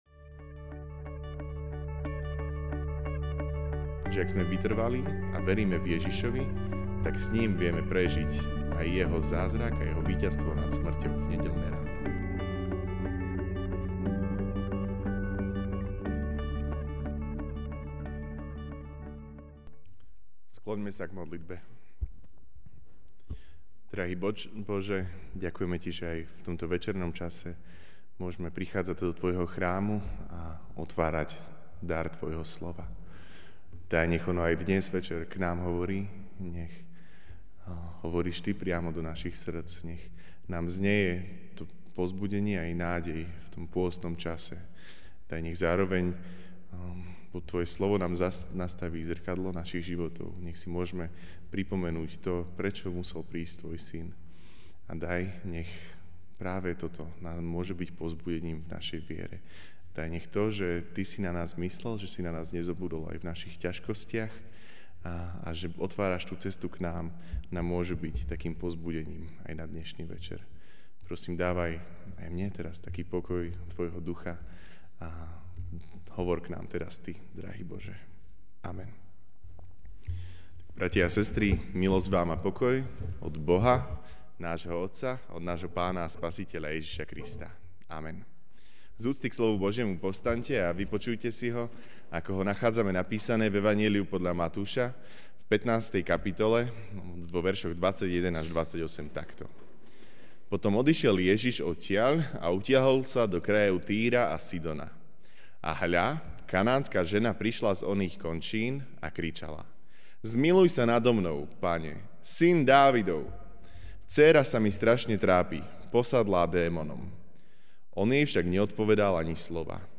Večerná kázeň: Neodraditeľná viera (Mt 15,21-28) 'Potom odišiel Ježiš a utiahol sa do krajov Týru a Sidonu.